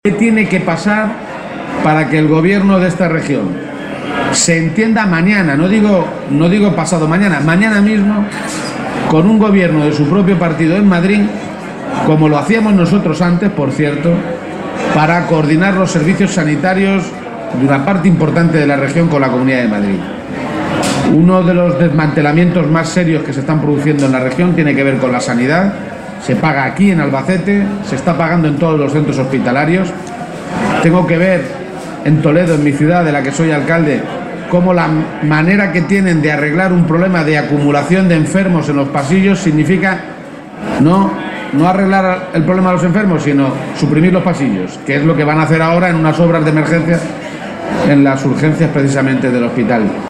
Page se pronunciaba de esta manera en su comparecencia ante los medios de comunicación, en Albacete, pocos minutos antes del acto de entrega de los Premios Pablo Iglesias que otorga la agrupación local socialista de la capital albaceteña.